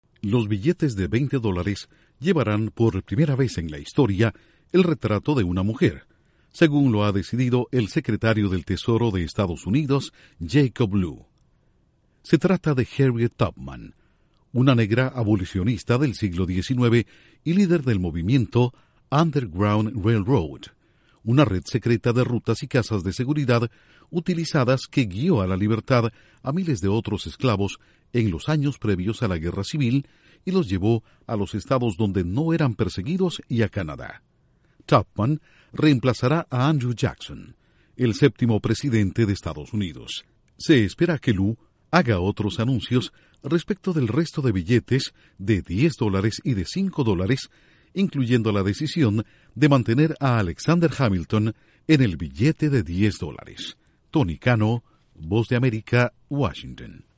El billete de veinte dólares de Estados Unidos tendrá el retrato de una mujer. Informa desde la Voz de América